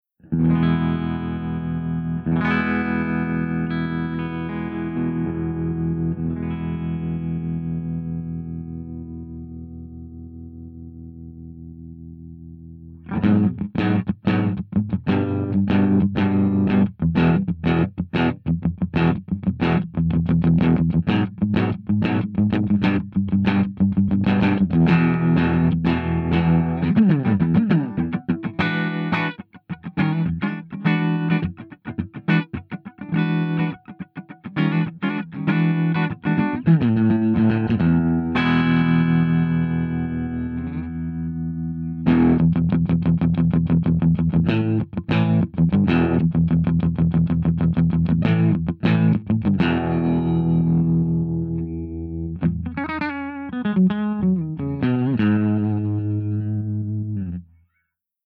071_FENDERSUPERREVERB_WARMTREMOLO_SC.mp3